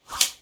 Close Combat Swing Sound 57.wav